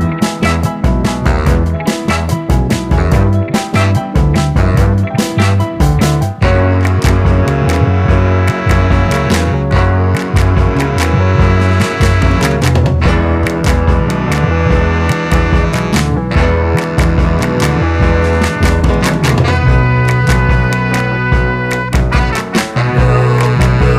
One Semitone Down Pop (2000s) 3:36 Buy £1.50